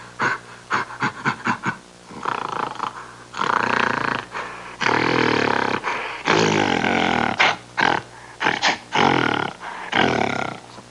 Gorilla Having Fun Sound Effect
Download a high-quality gorilla having fun sound effect.
gorilla-having-fun.mp3